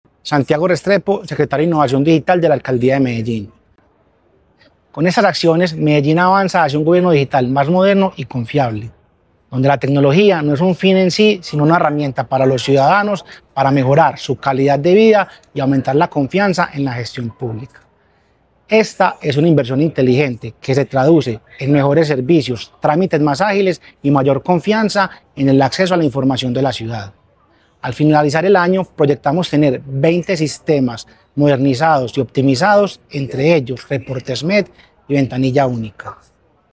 Declaraciones del secretario de Innovación Digital, Santiago Restrepo Arroyave.
Declaraciones-del-secretario-de-Innovacion-Digital-Santiago-Restrepo-Arroyave..mp3